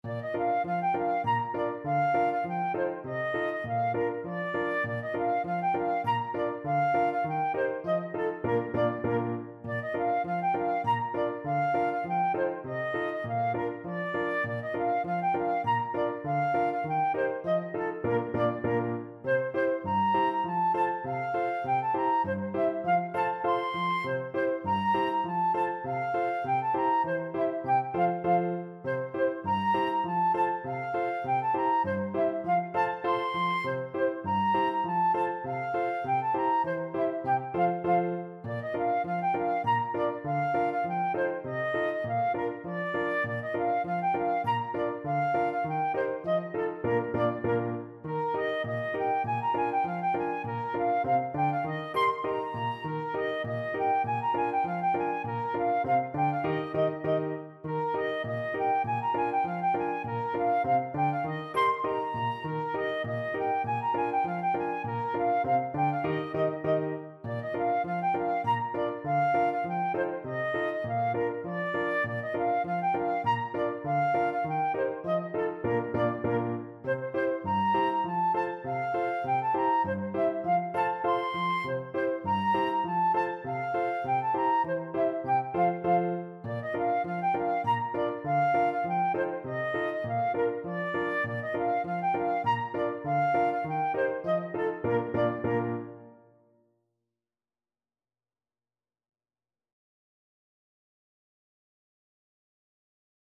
Traditional Trad. Pincushion Polka (Bunch of Violets) Flute version
Flute
2/4 (View more 2/4 Music)
A5-C7
Bb major (Sounding Pitch) (View more Bb major Music for Flute )
Traditional (View more Traditional Flute Music)
pincushion_polkaFL.mp3